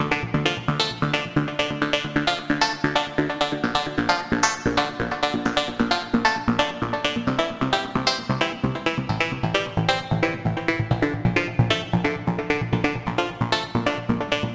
锐意进取的高频率合成器
描述：高频率的硬狂欢合成器
Tag: 132 bpm Rave Loops Synth Loops 2.45 MB wav Key : Unknown